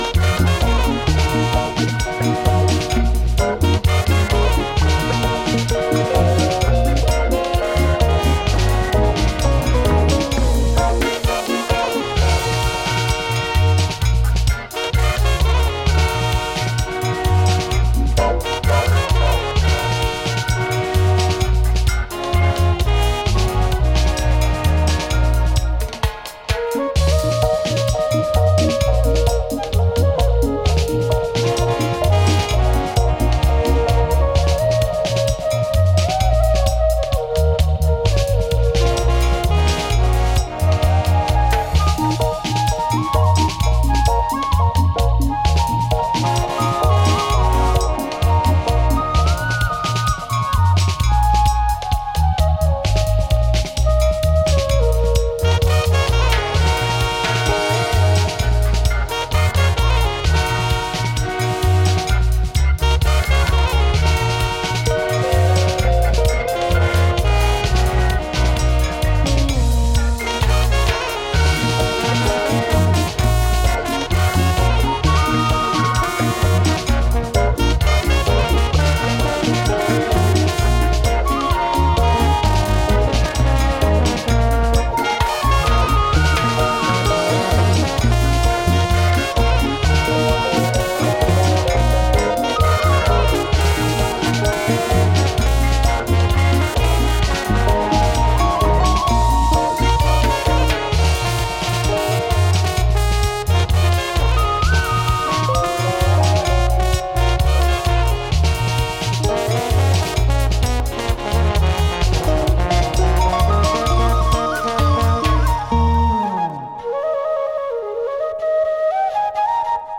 Reggae / Dub